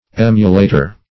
Emulator \Em"u*la`tor\, n. [L. aemulator.]